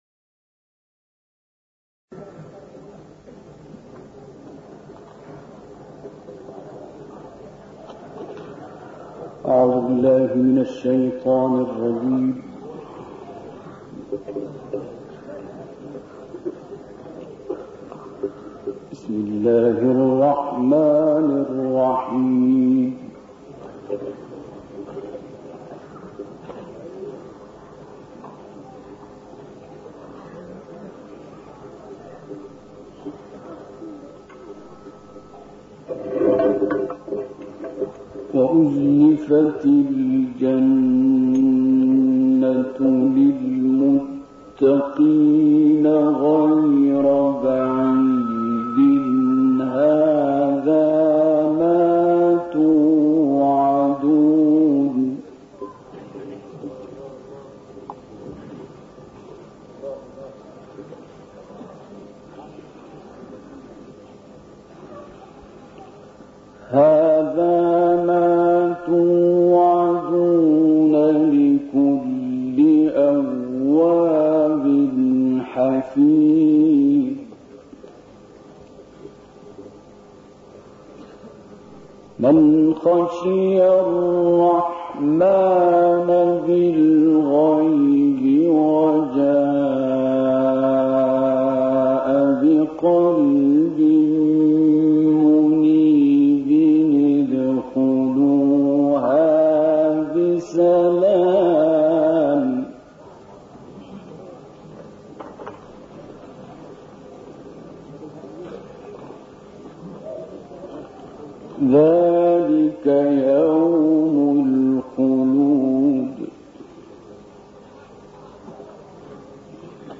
সুললিত কণ্ঠে আব্দুল বাসিতের কুরআন তিলাওয়াত
বার্তা সংস্থা ইকনা: মিশরের এই ক্বারি সেদেশের উরমিনাট শহরে কুরআন তিলাওয়াত করেছেন। ১৯৭৮ সালে উরমিনাট শহরে বিশ্ববরেণ্য ক্বারি আব্দুল বাসিত পবিত্র কুরআনের সূরা কাফের ৩১ থেকে ৪৫ নম্বর আয়াত এবং সূরা ইনফিতার, সূরা বালাদ, সূরা দুহা, সূরা ইনশিরাহ, সূলা তীন এবং সূরা কাদর তিলাওয়াত করেছেন।